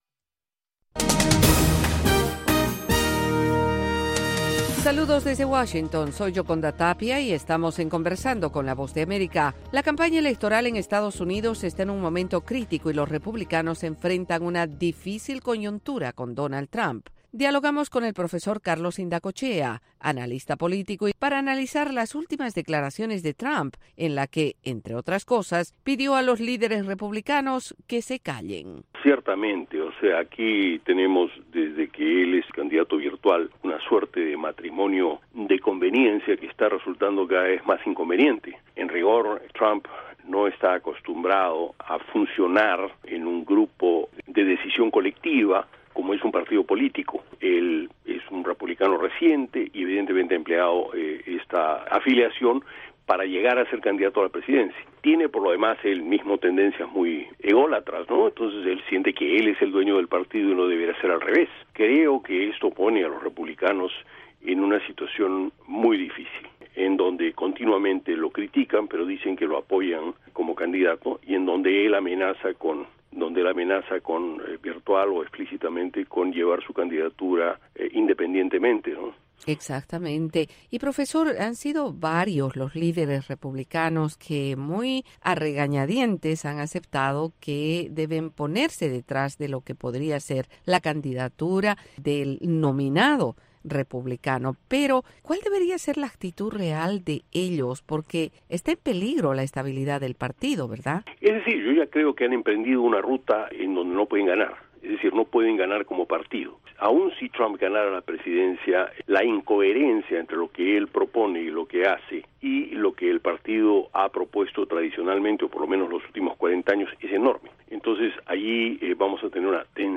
Entrevista VOA - 12:30pm
La Voz de América entrevista, en cinco minutos, a expertos en diversos temas.